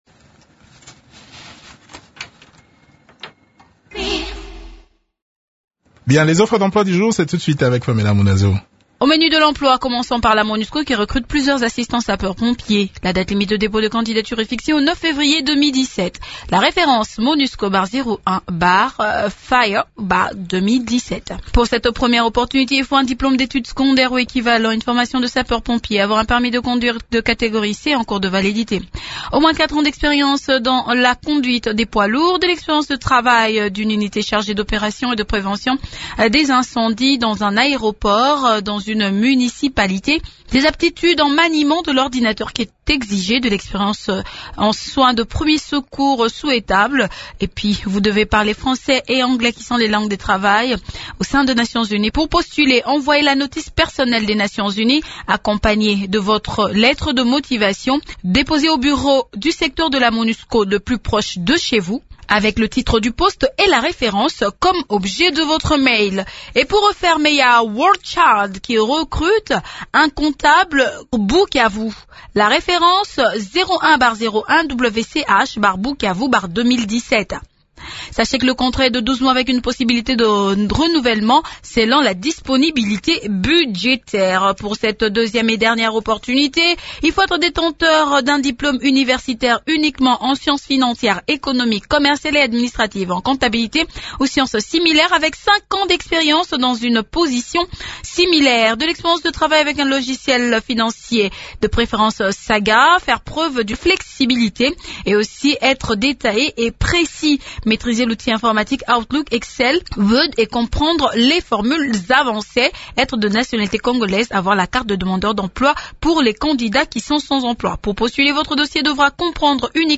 Le point de la situation sur terrain dans cet entretien